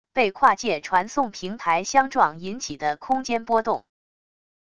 被跨界传送平台相撞引起的空间波动wav音频